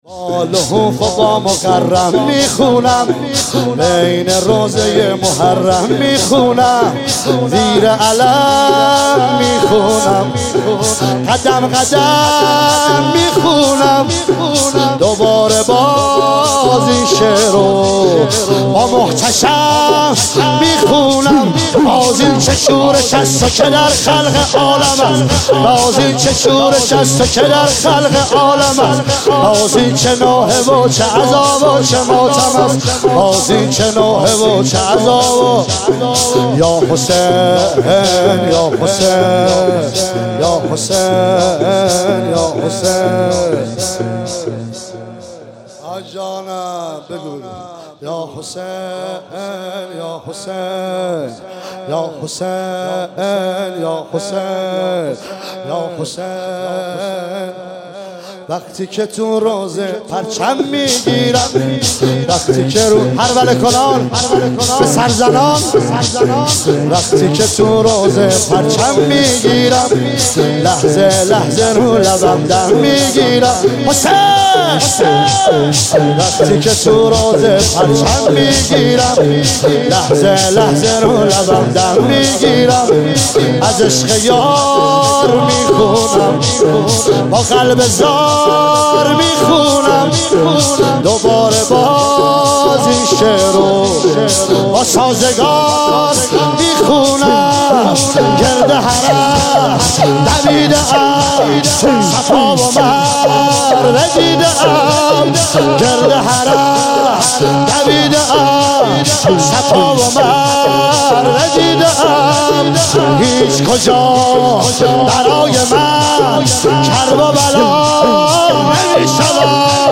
عنوان شب چهارم محرم الحرام ۱۳۹۸
شور